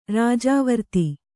♪ rājāvarti